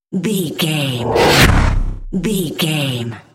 Scifi whoosh pass by
Sound Effects
futuristic
high tech
intense
pass by